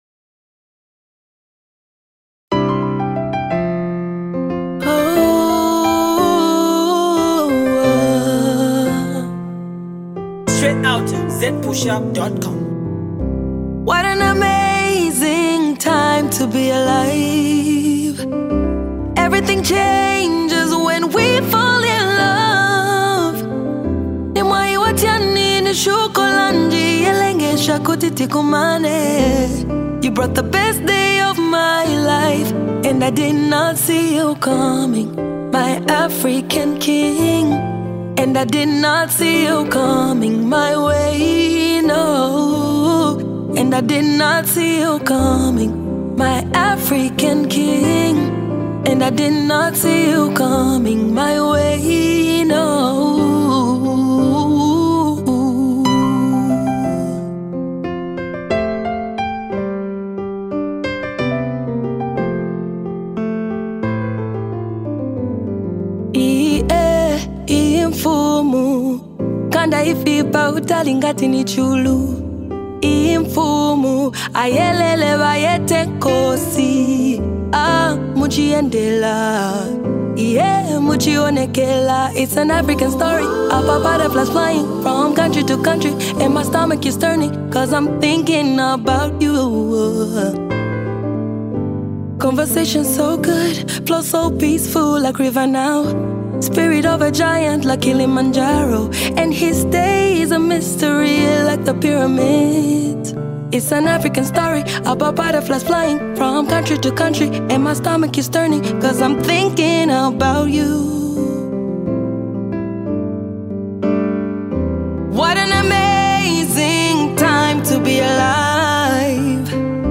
Zambia’s very own Cultural singer